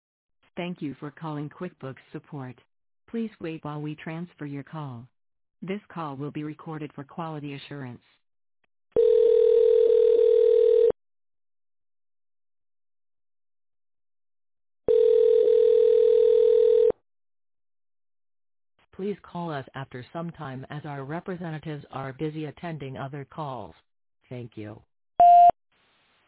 Voicemail (after hours)